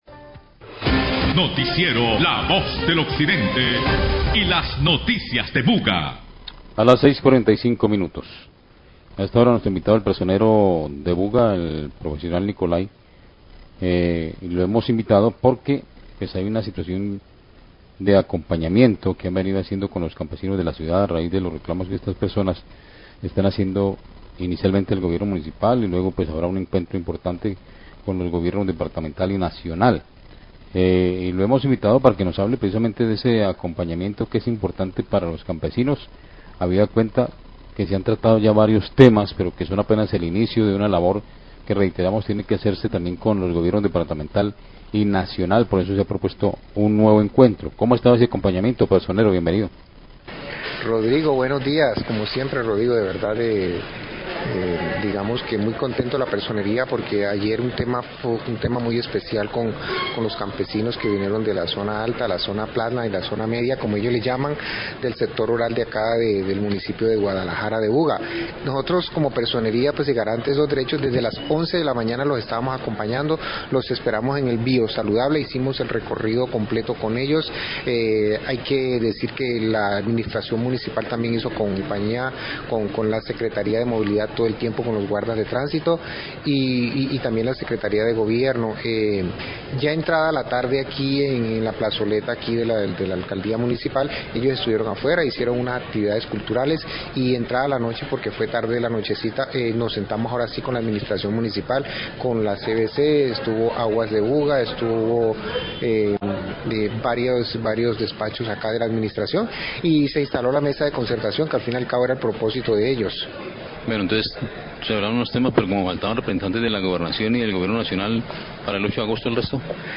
Personero Buga habla del acompañamiento a la mesa de concertación con cumunidades campesinas
Radio